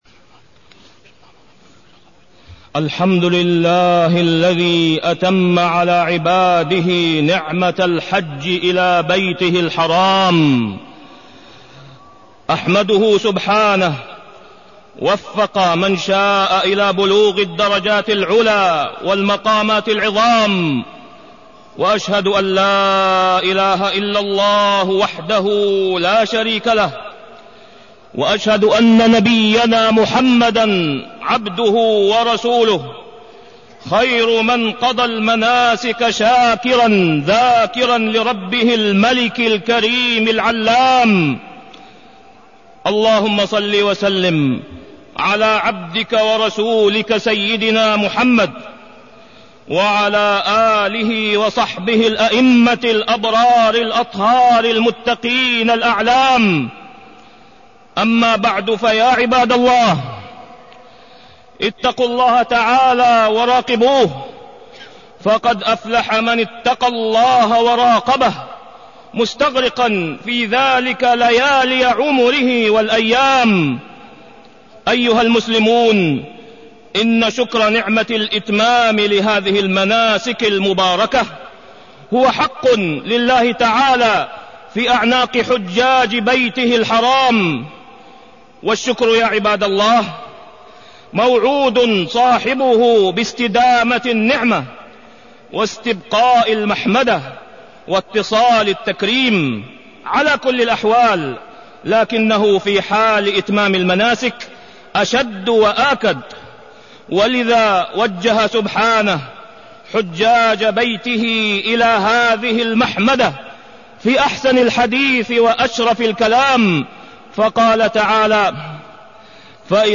تاريخ النشر ١٤ ذو الحجة ١٤٢١ هـ المكان: المسجد الحرام الشيخ: فضيلة الشيخ د. أسامة بن عبدالله خياط فضيلة الشيخ د. أسامة بن عبدالله خياط حسنة الدنيا وحسنة الآخرة